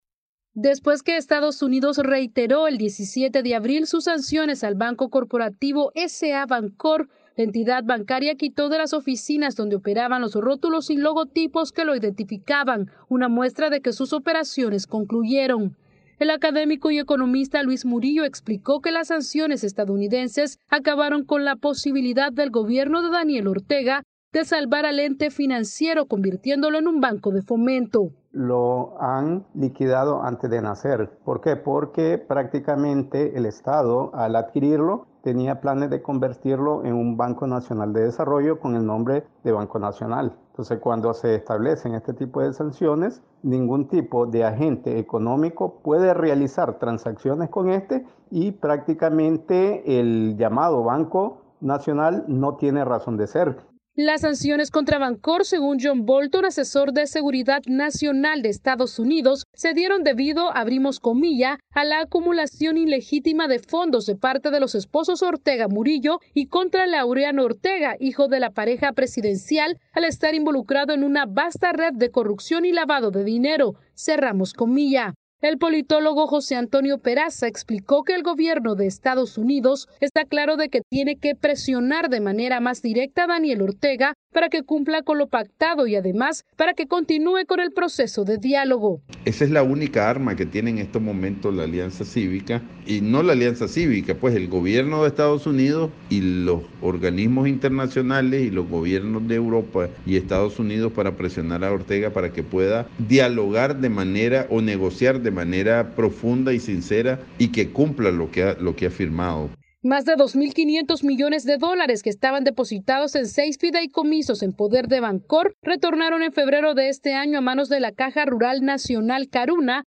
VOA: Informe desde Nicaragua